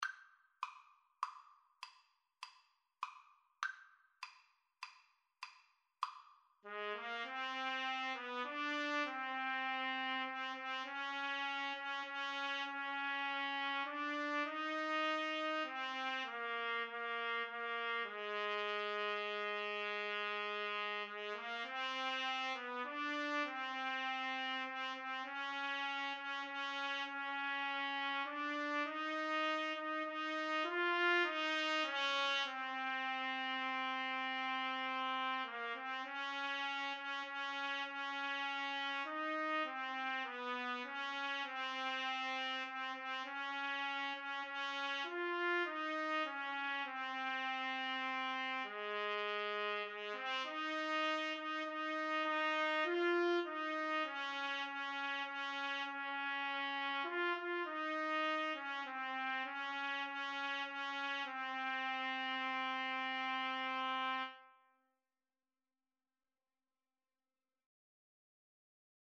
6/8 (View more 6/8 Music)
Classical (View more Classical Trumpet Duet Music)